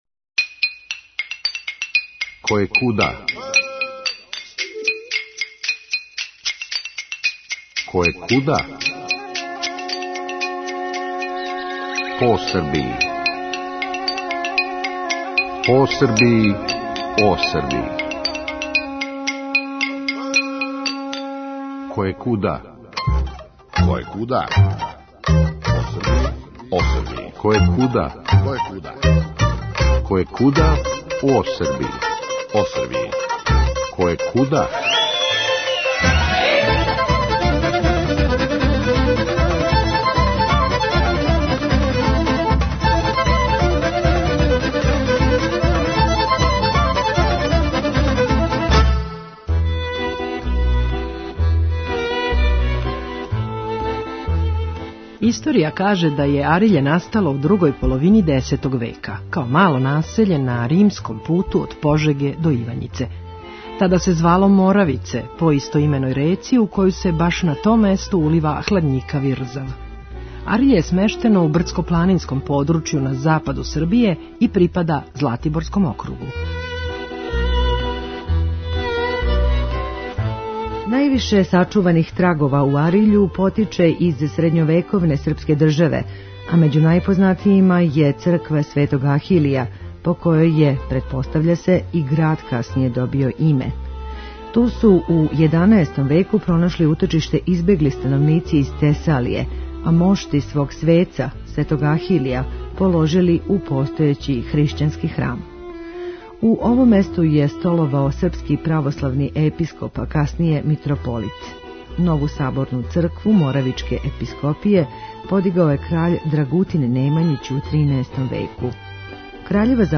У овој којекуда причи пребираћемо по сећањима и прошлости. Наше саговорнике пронашле смо у околини града, и затекли их у великом послу.